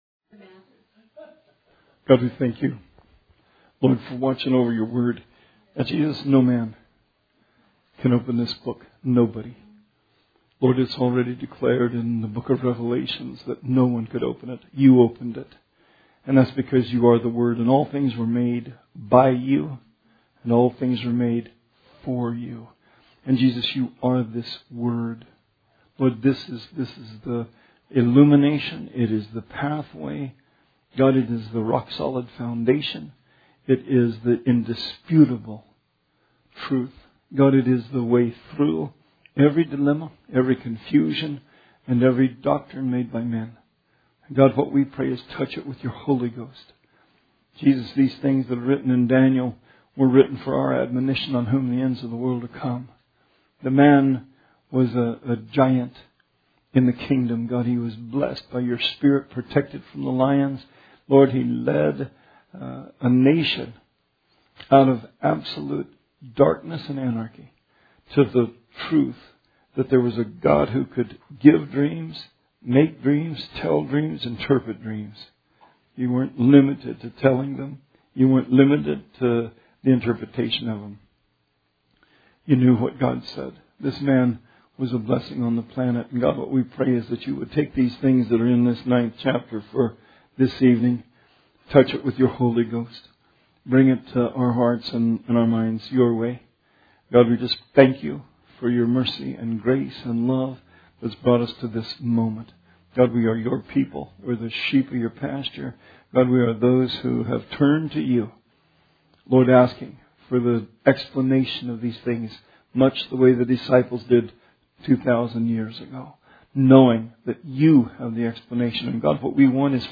Bible Study 9/9/20